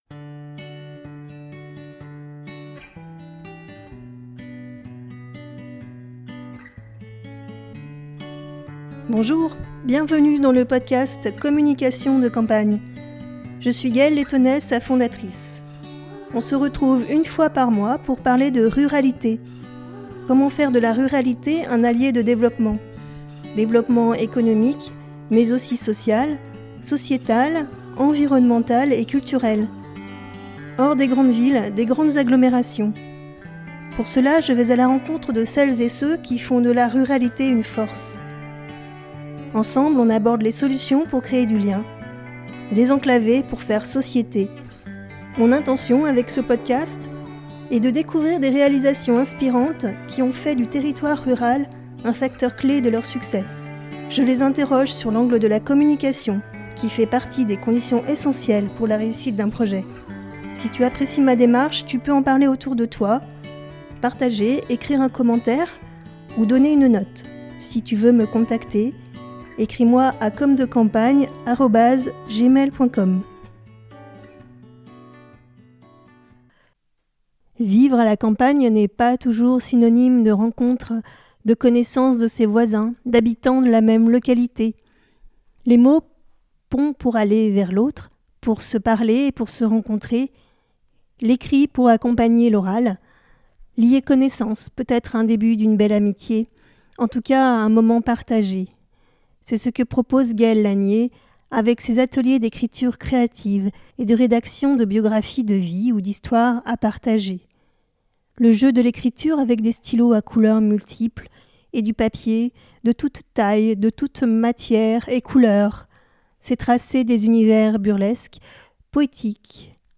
Communication de campagne Interview